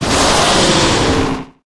Cri d'Hydragla dans Pokémon HOME.